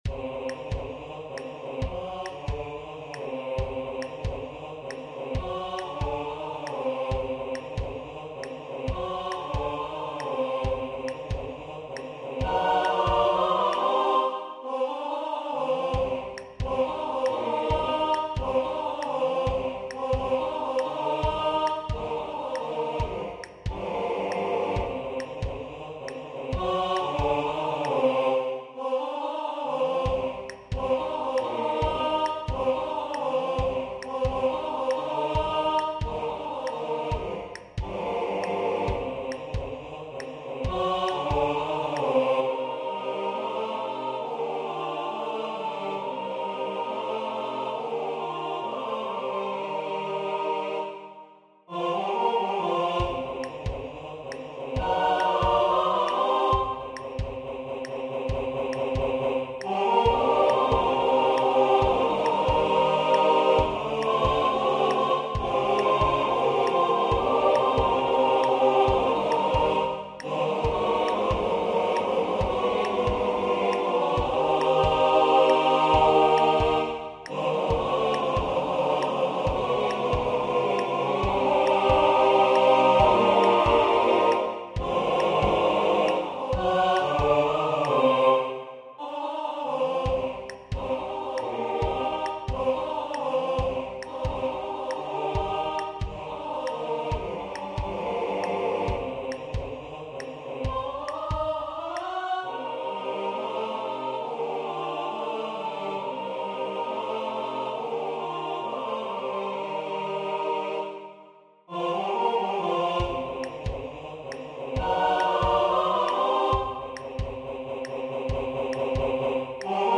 Arrangement pour chœur